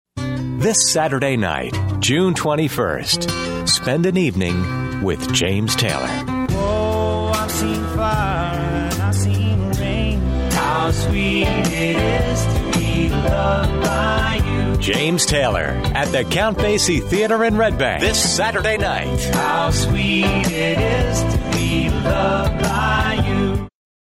Male
The vibe is Warm, Friendly, Familiar, and Trustworthy, with just enough Edge to keep it interesting.
Music Promos
Soft Rock - James Taylor